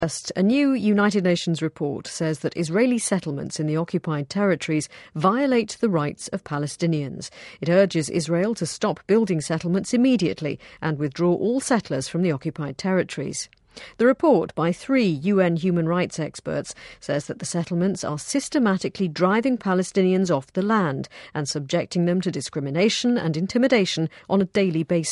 【英音模仿秀】以色列定居点问题争端再起 听力文件下载—在线英语听力室